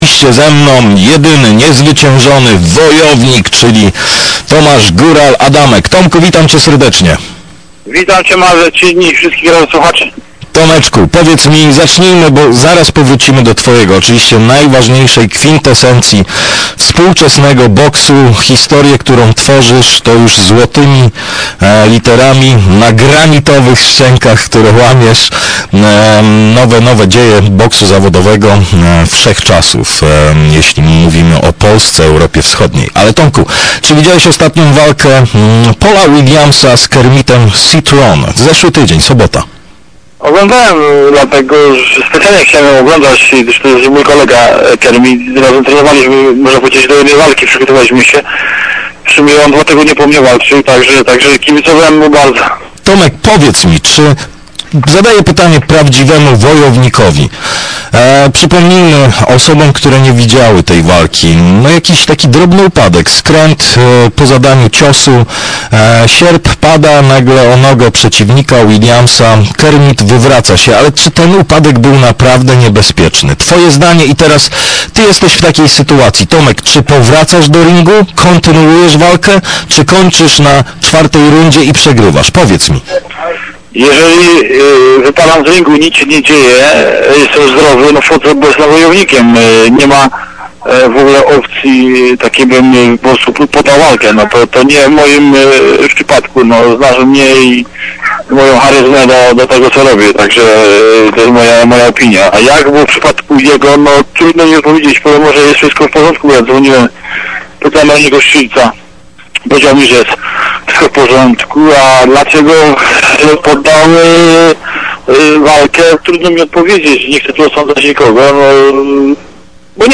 Wywiad z Adamkiem.